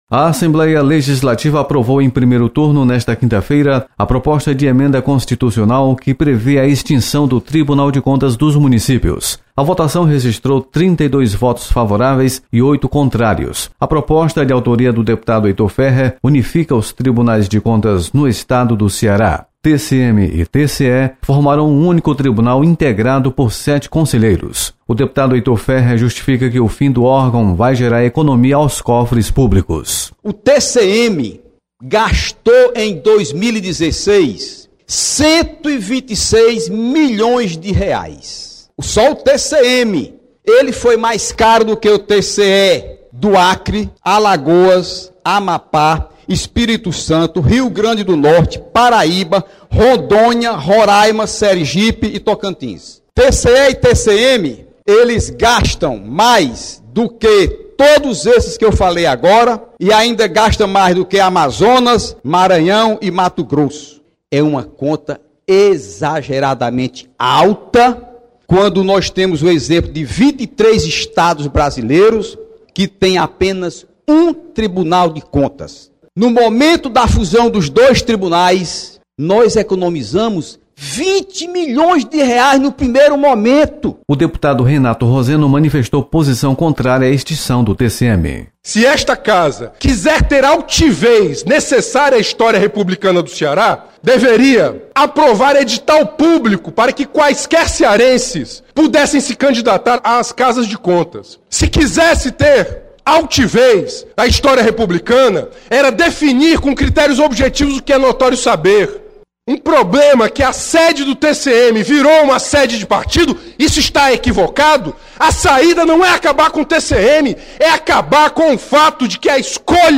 Assembleia Legislativa aprova  extinção do TCM e LDO 2018. Repórter